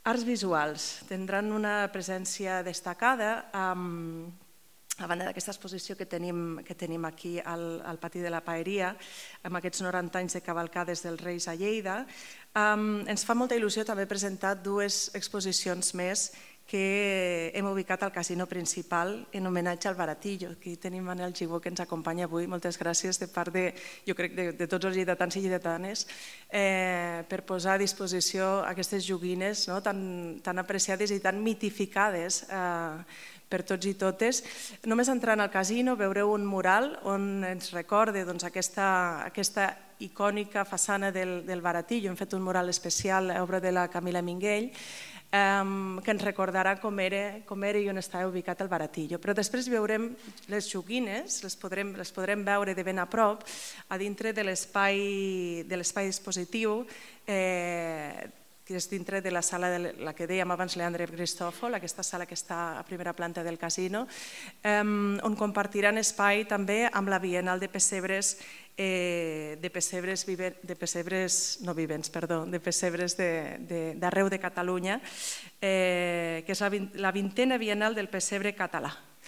Talls de veu
Parlament de la consellera Sílvia Paneque.